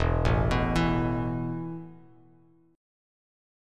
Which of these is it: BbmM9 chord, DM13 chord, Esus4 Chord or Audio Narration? Esus4 Chord